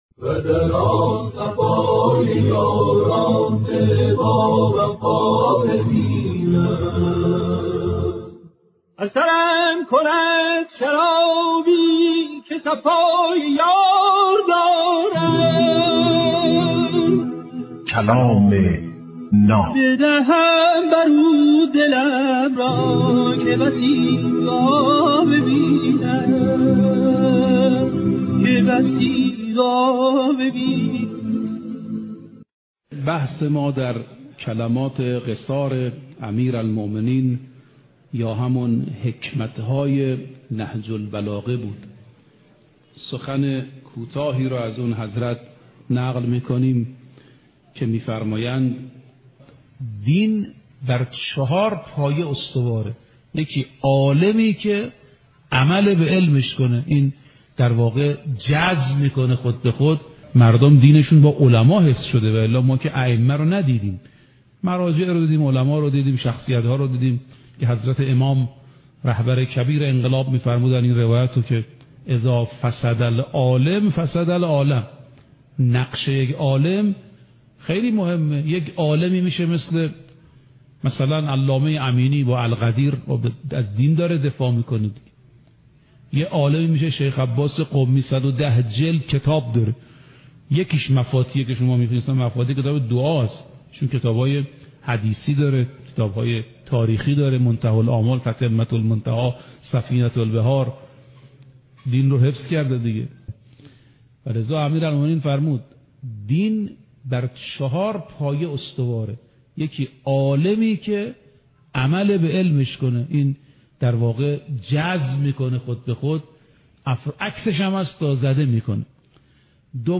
کلام ناب برنامه ای از سخنان بزرگان است